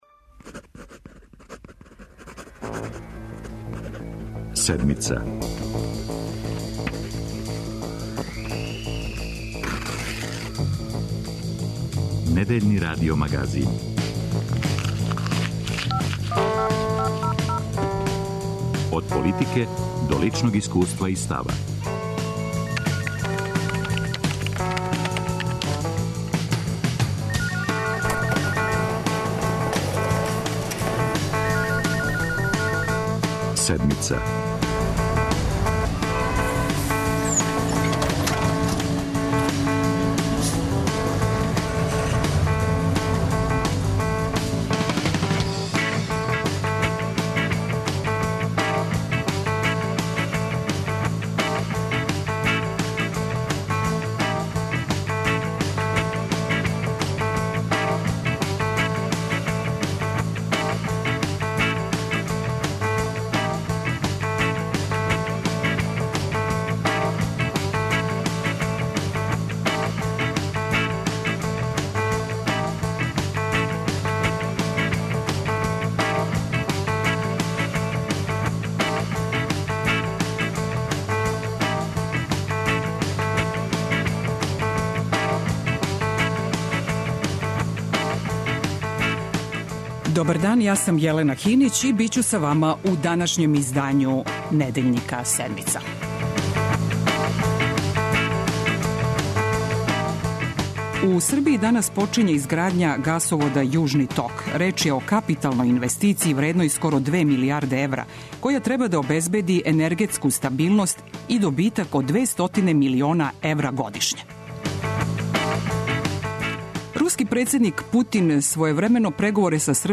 О Јужном току који би Србији требало да донесе енергетску стабилност и годишњу добит од 200 милиона евра, за Сeдмицу говоре: Министарка енергетике Зорана Михајловић